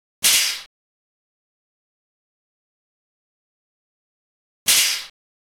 transport
Truck Brake Release